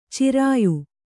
♪ cirāyu